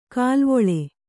♪ kālvoḷe